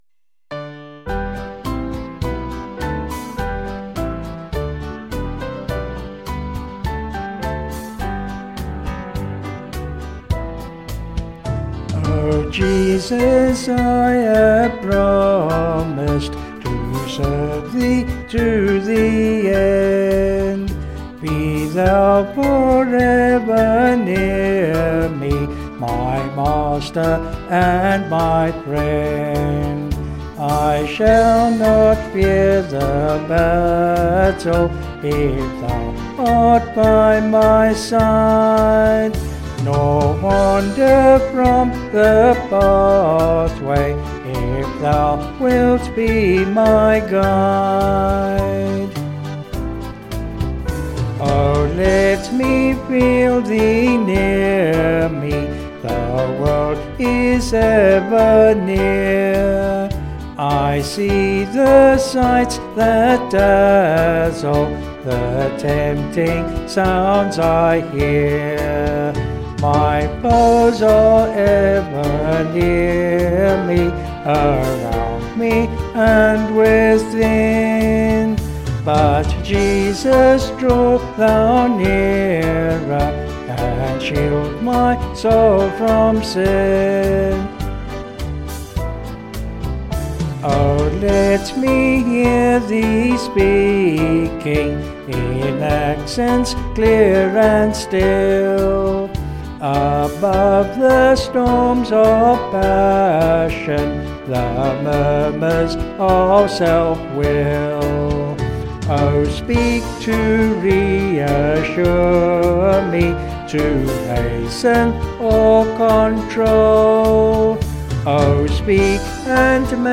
Vocals and Organ   264.5kb Sung Lyrics 3.4mb